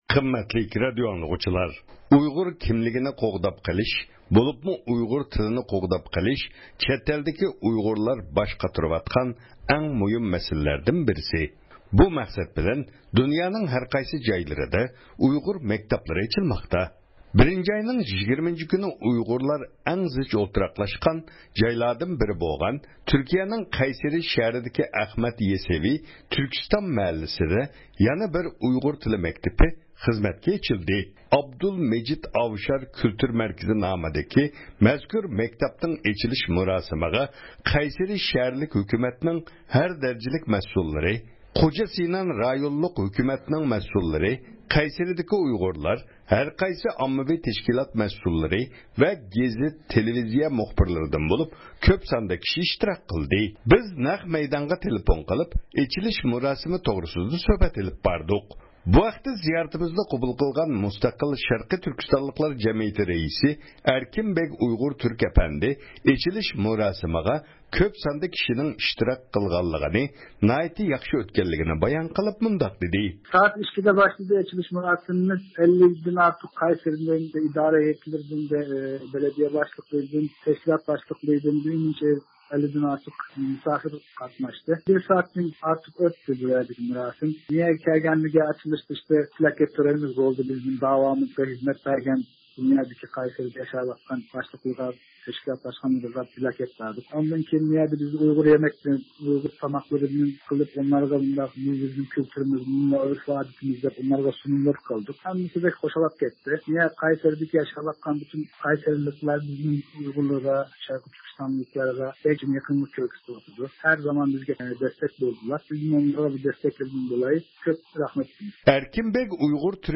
بىز نەق مەيدانغا تېلېفون قىلىپ ئېچىلىش مۇراسىمى توغرىسىدا سۆھبەت ئېلىپ باردۇق.